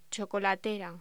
Locución: Chocolatera
voz